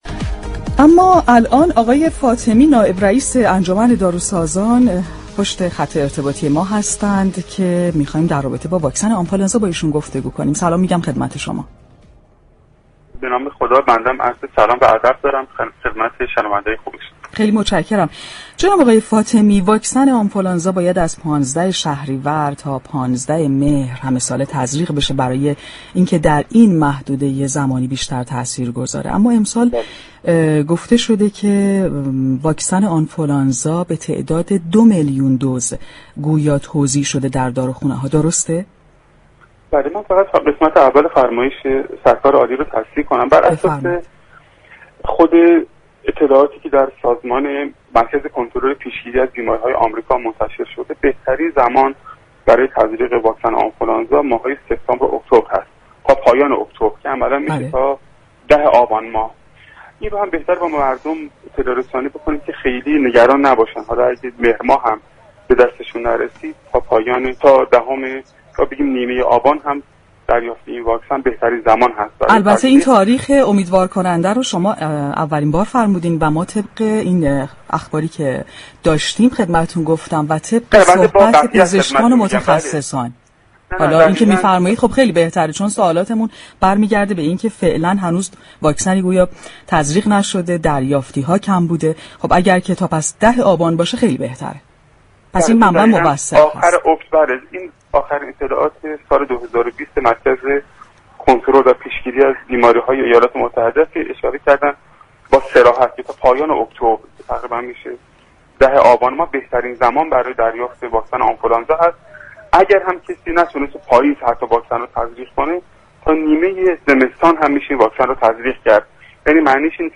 رویداد